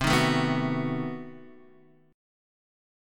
B7/C chord